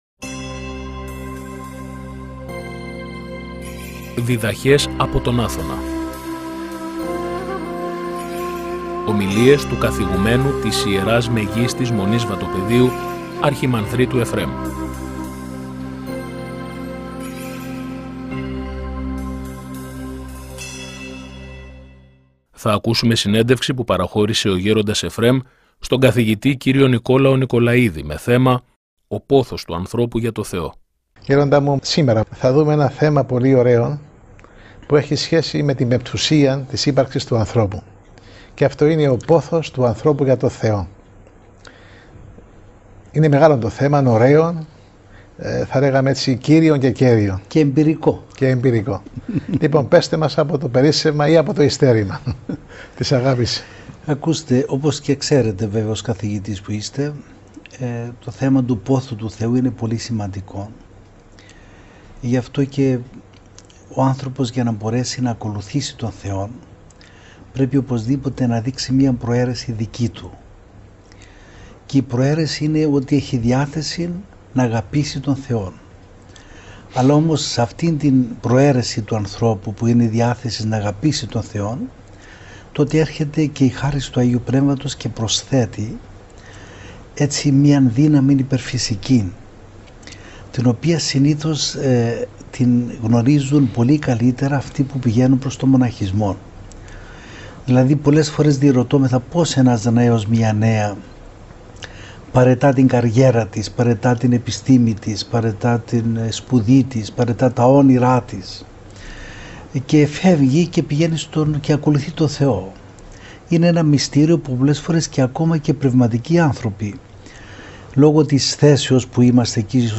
Συνέντευξη του Καθηγουμένου της Ιεράς Μεγίστης Μονής Βατοπαιδίου Γέροντος Εφραίμ στον ομ.